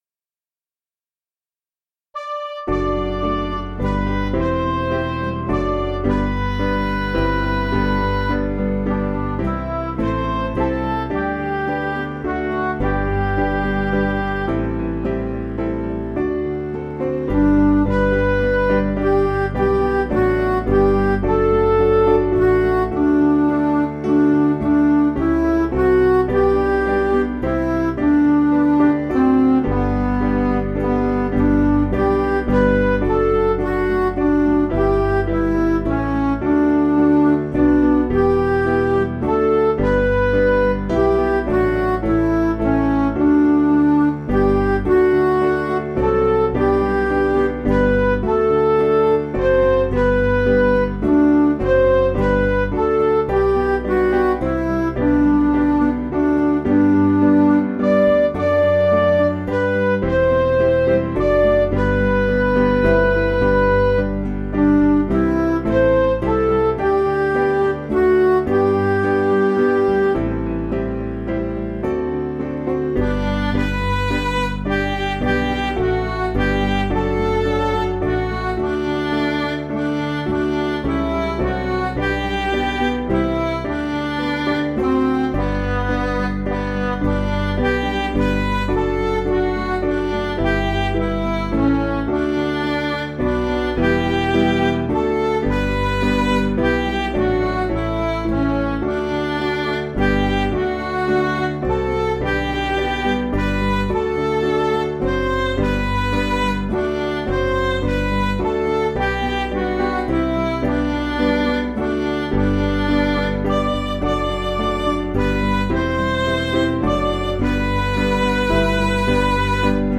Piano & Instrumental
Midi